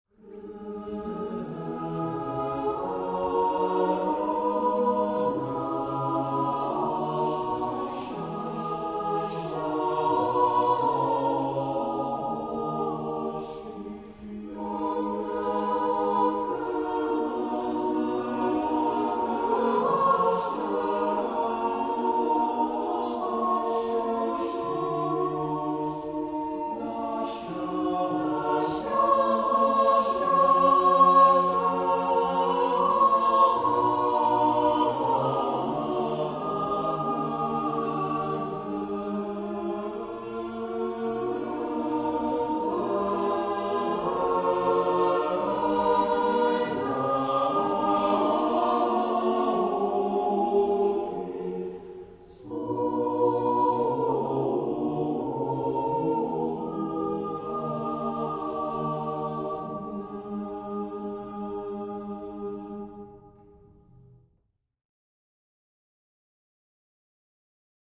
wykonuje chór mieszany